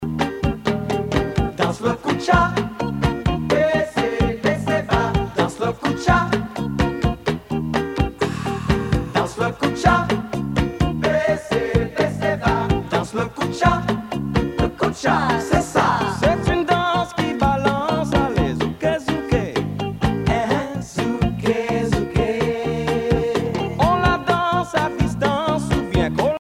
gestuel : danse